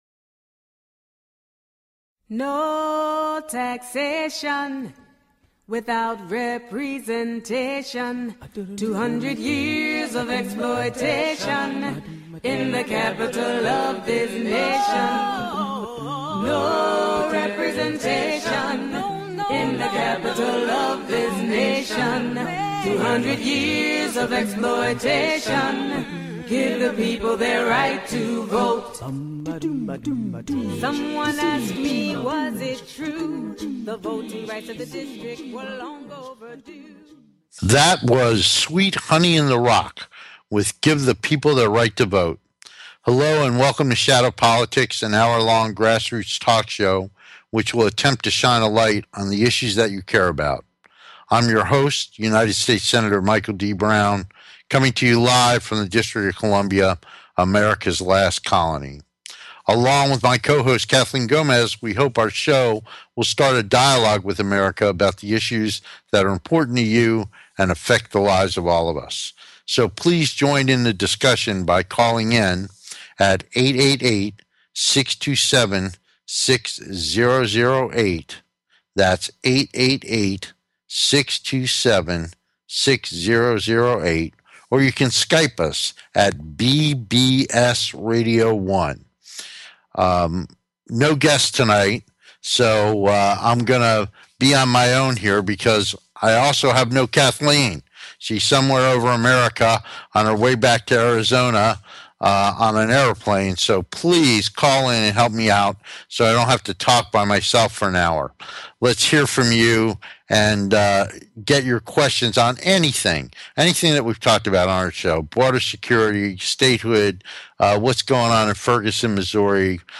Talk Show Episode, Audio Podcast, Shadow Politics and with Senator Michael D. Brown on , show guests , about DC Politics, categorized as News,Philosophy,Politics & Government